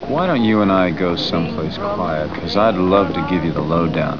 seduce con un tono de voz que parece más claro y nítido que el resto de las voces y de la música de la escena.
Confidential-quiet.wav